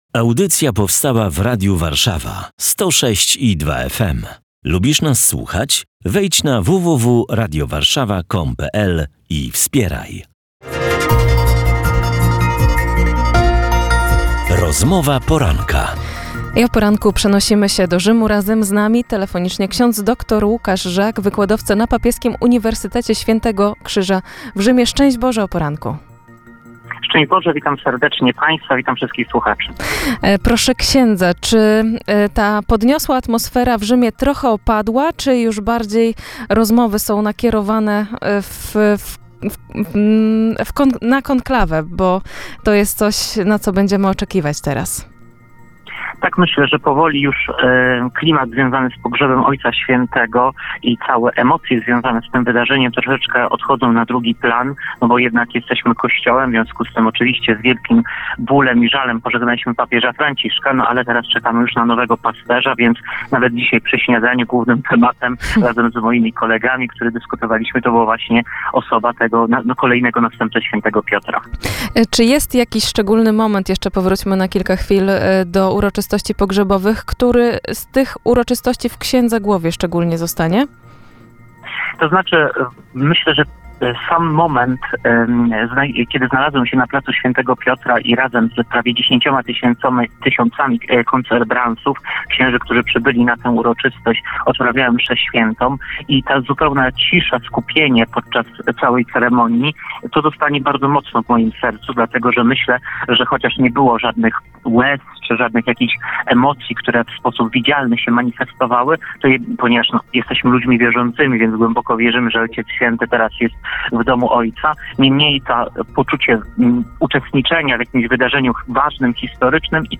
Watykan-żałoba i przygotowania do konklawe - Radio Warszawa 106,2 FM